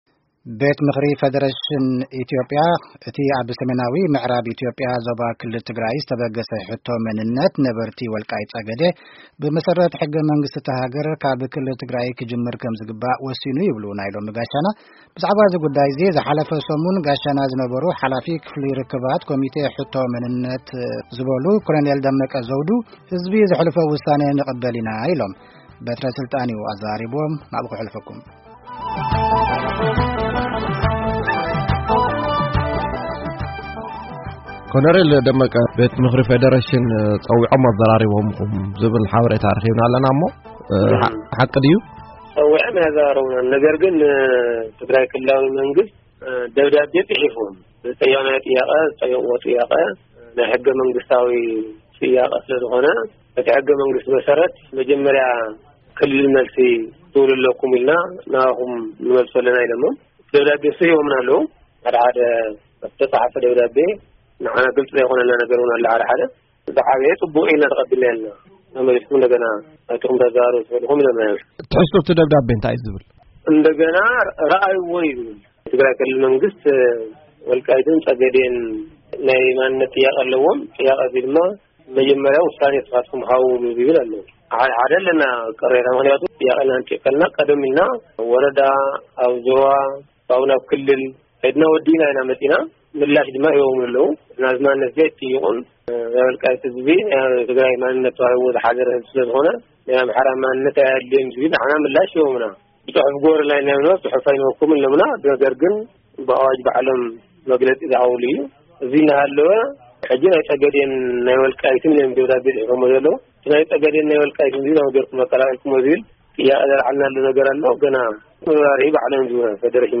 ቃለ መጠይቅ